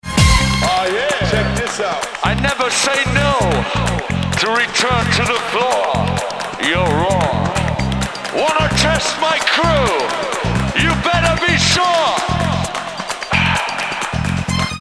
"плямкающий" повторяющийся звук уходящий в левый канал.